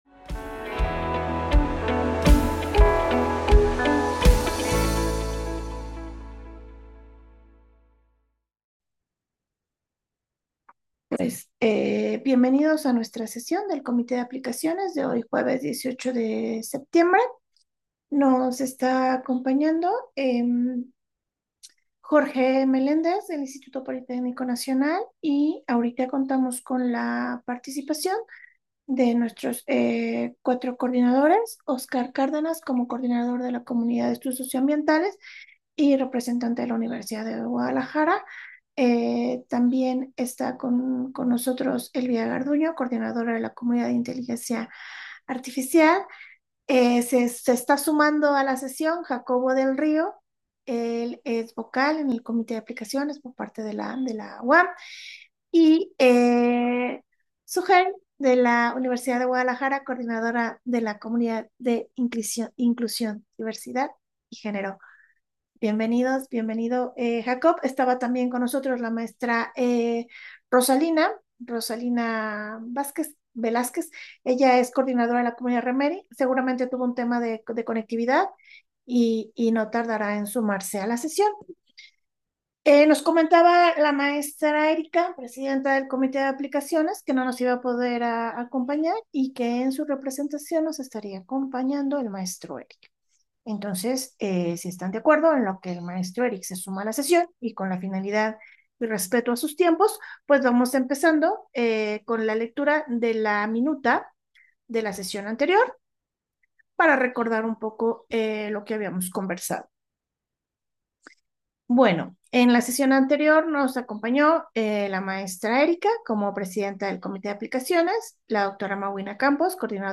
Audio de la reunión